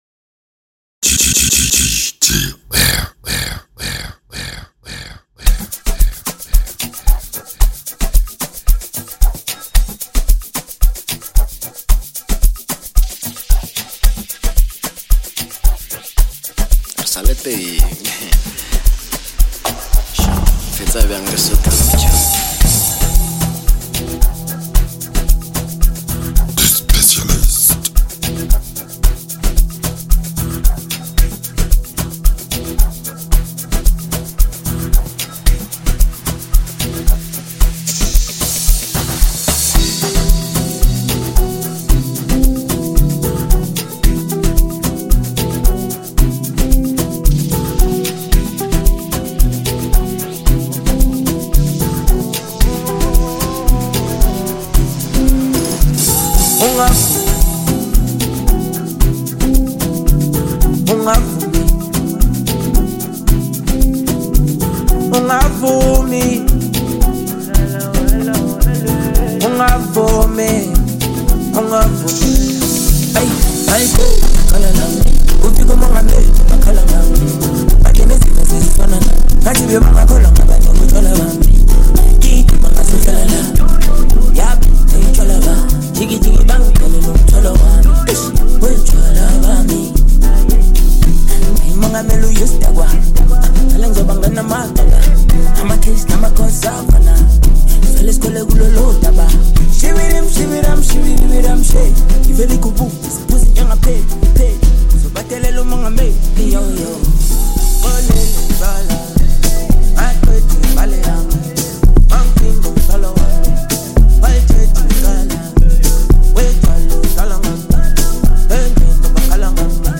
Welcome in Amapiano country !!